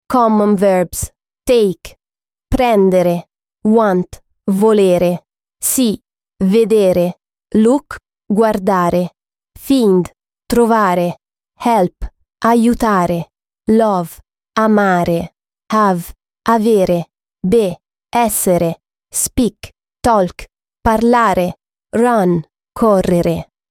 Lesson 5